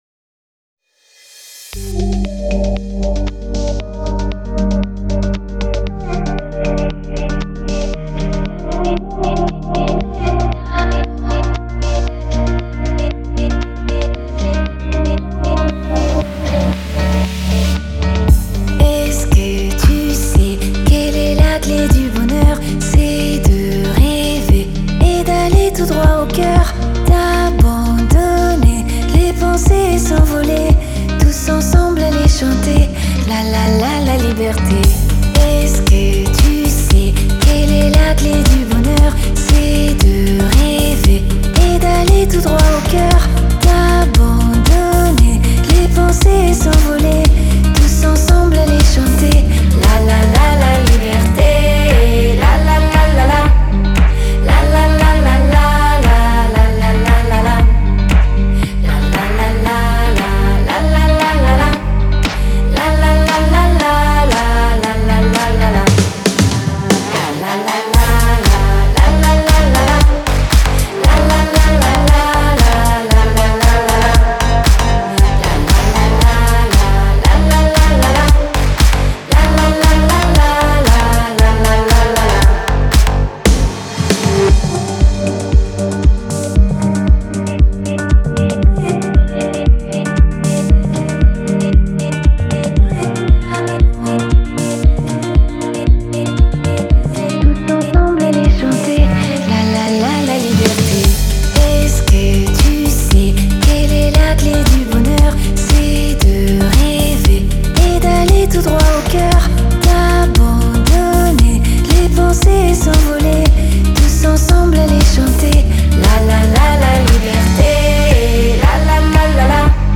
это энергичный трек в жанре танцевальной музыки